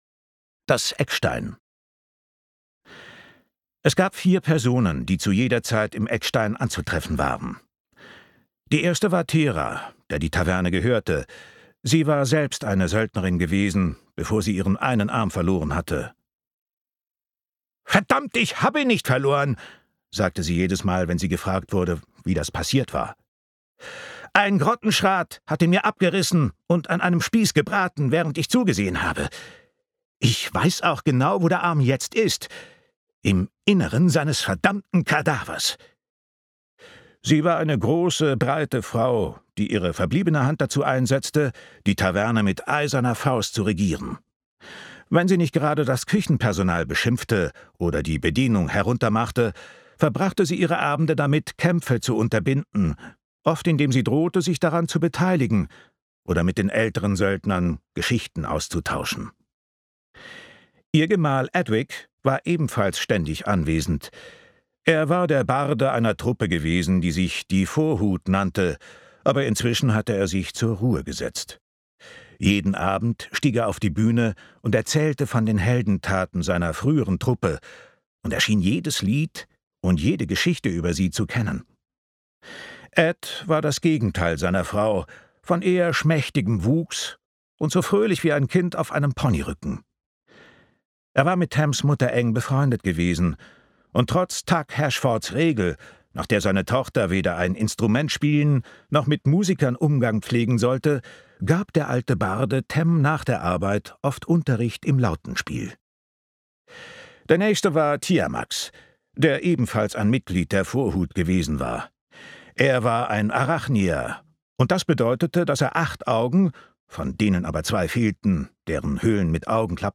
Hörprobe Projekt Pegasus Will Jordan